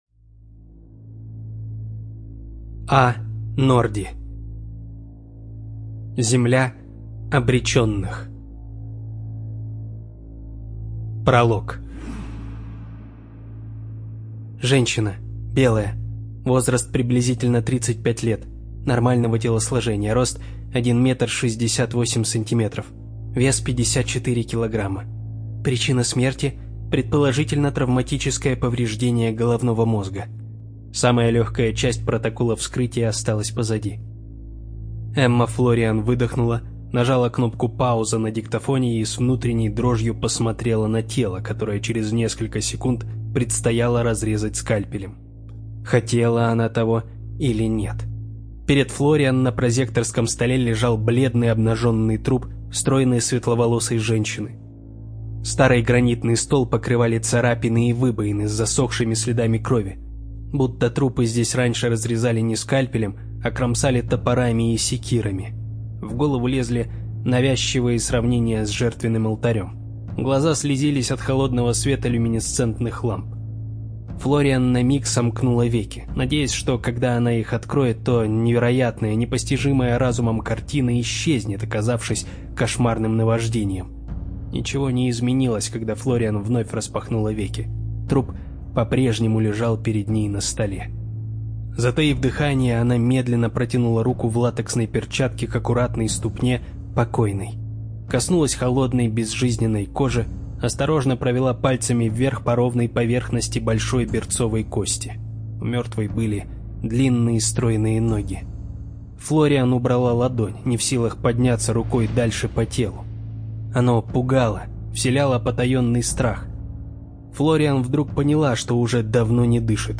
ЖанрФантастика, Детективы и триллеры